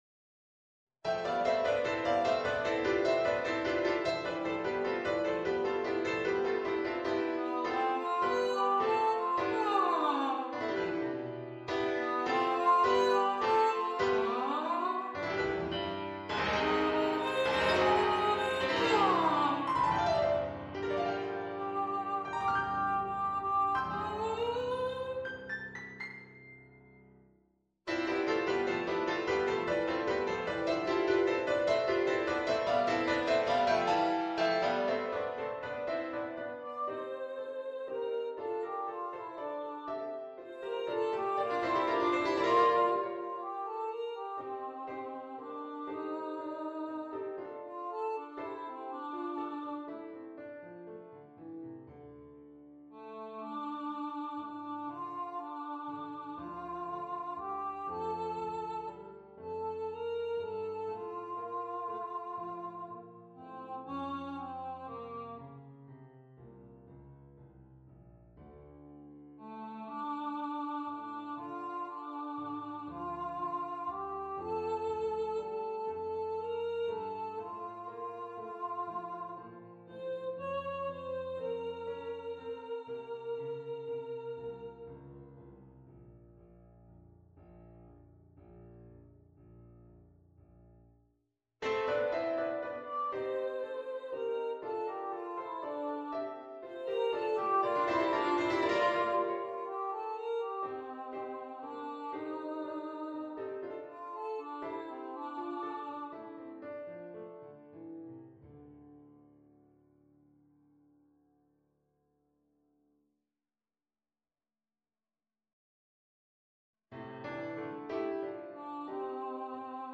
synthesized rendition